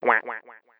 Sound Buttons: Sound Buttons View : Duck Button
duck-button.mp3